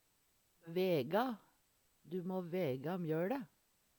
vega - Numedalsmål (en-US)